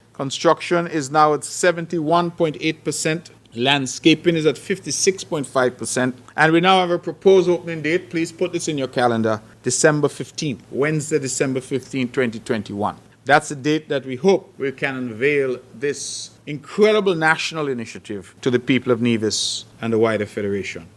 Here is Mr. Brantley
Premier, Mark Brantley